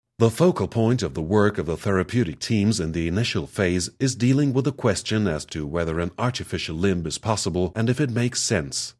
sprecherdemos
englisch m_02